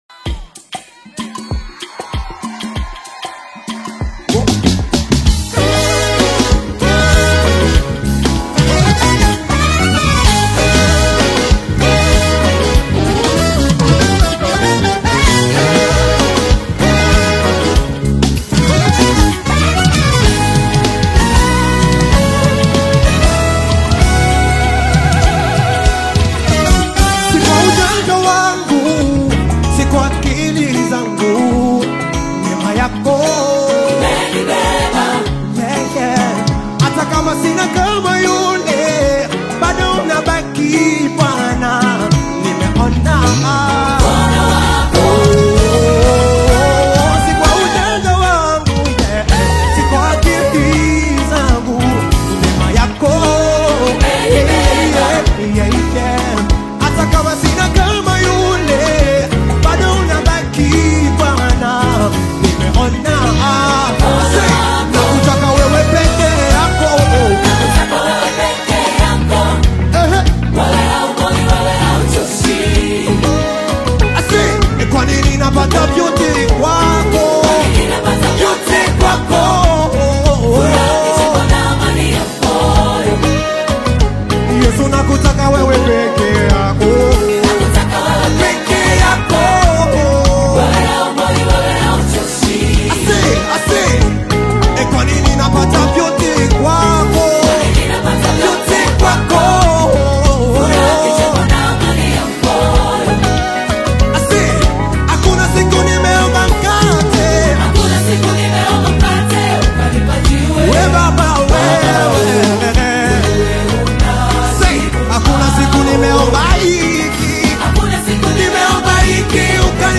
heartfelt Swahili gospel single
Tanzanian worship ensemble